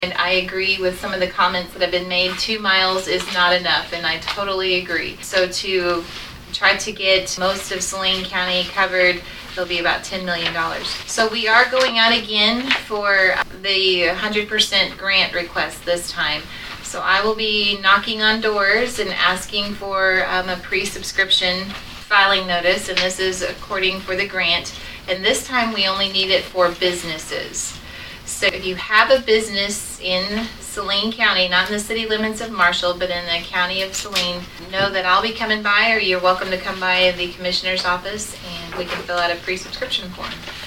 During the meeting of the Saline County Commission on Tuesday, February 25, Northern District Commissioner Stephanie Gooden gave an update on rural broadband.